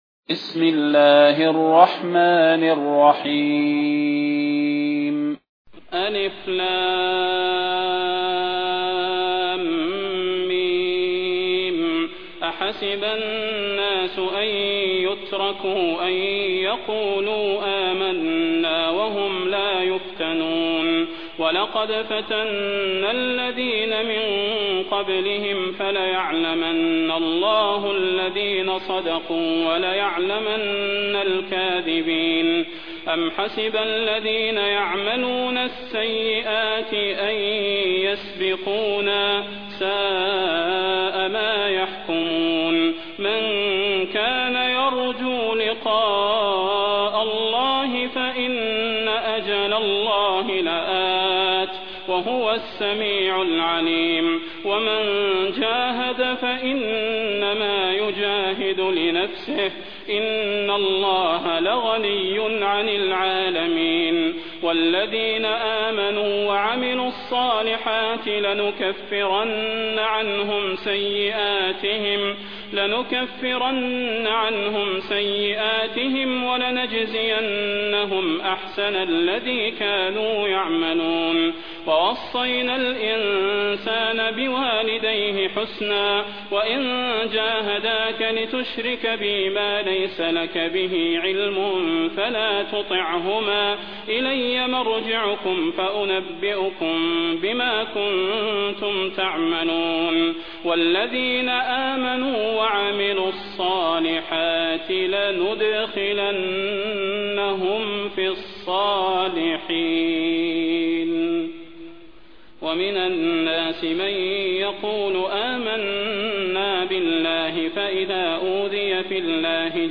تلاوة سورة العنكبوت
فضيلة الشيخ د. صلاح بن محمد البدير
المكان: المسجد النبوي الشيخ: فضيلة الشيخ د. صلاح بن محمد البدير فضيلة الشيخ د. صلاح بن محمد البدير سورة العنكبوت